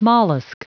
Prononciation du mot mollusc en anglais (fichier audio)
mollusc.wav